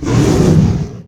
CosmicRageSounds / ogg / general / combat / creatures / dragon / he / hurt2.ogg
hurt2.ogg